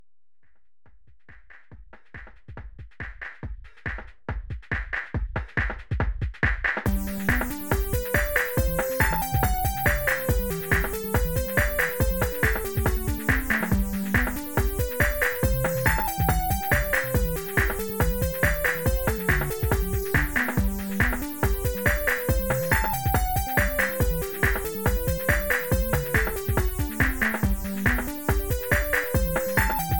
“Lo-Fi Cybertrad”